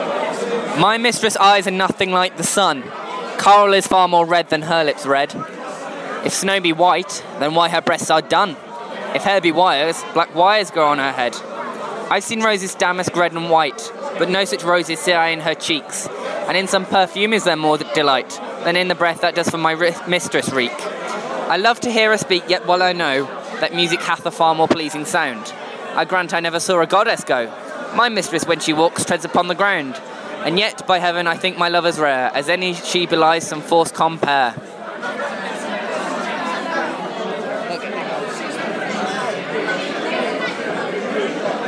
at The Figure Of Eight pub in Birmingham on 22.03.13.